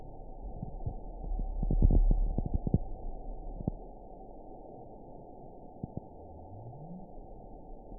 event 921627 date 12/12/24 time 20:37:42 GMT (5 months ago) score 9.62 location TSS-AB04 detected by nrw target species NRW annotations +NRW Spectrogram: Frequency (kHz) vs. Time (s) audio not available .wav